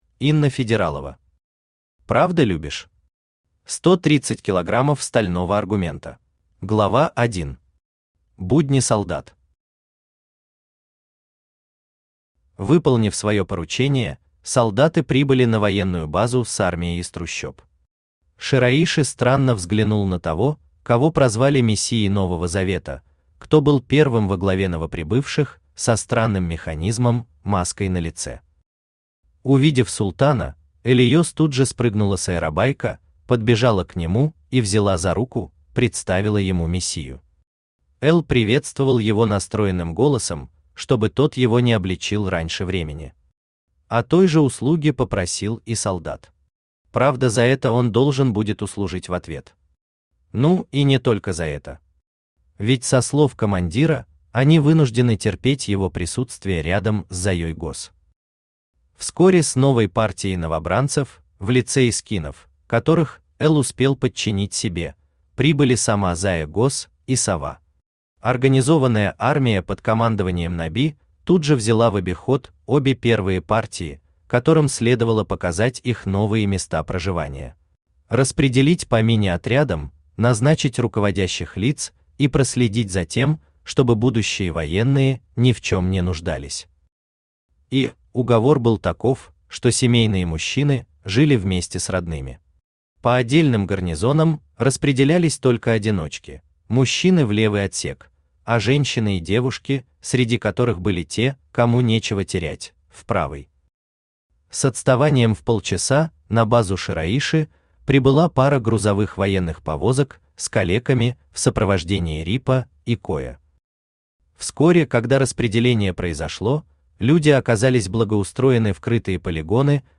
Аудиокнига Правда любишь? 130 кг стального аргумента | Библиотека аудиокниг
Aудиокнига Правда любишь? 130 кг стального аргумента Автор Инна Федералова Читает аудиокнигу Авточтец ЛитРес.